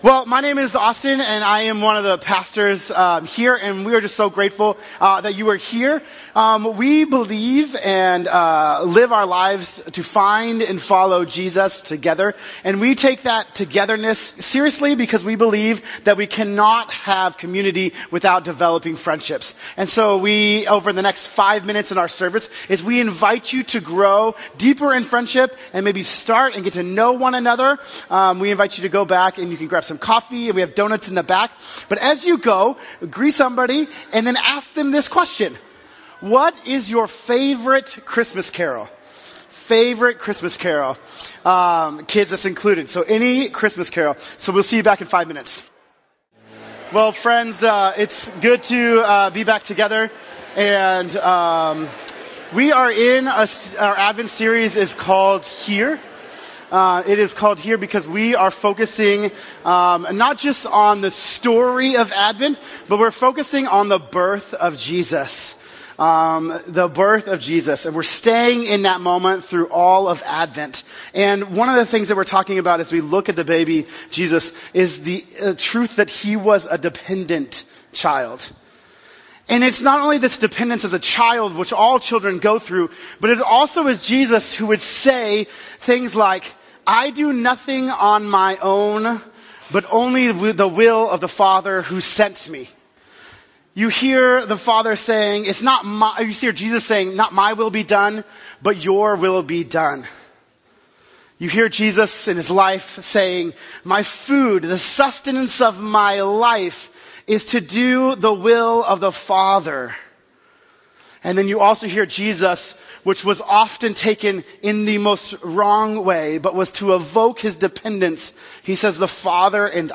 Also, on this second Sunday of Advent—Peace Sunday—we’re celebrating with an entire service of music, with an orchestra and full band!